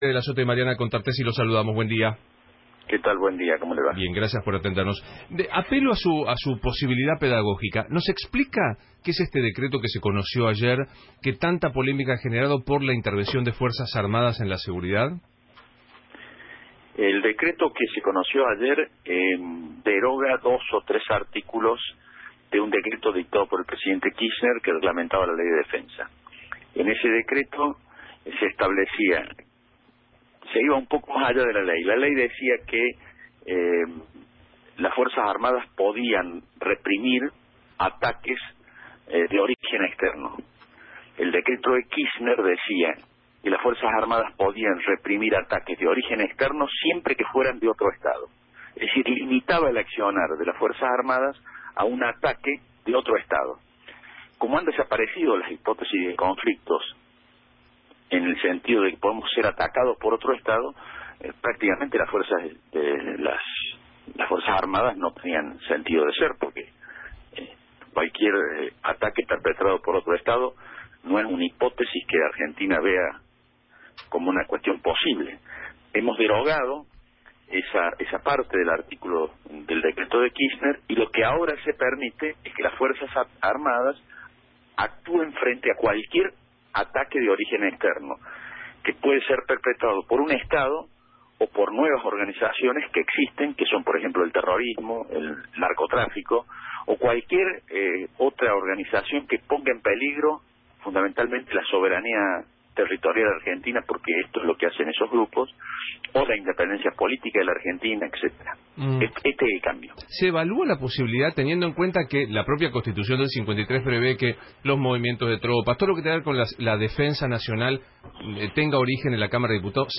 Durante una entrevista en radio La Red, Aguad explicó además que la idea de que las FF.AA. actúen contra el narcotráfico es “para evitar que las bandas se instalen” en territorio argentino, pero que no van a reemplazar a la Gendarmería.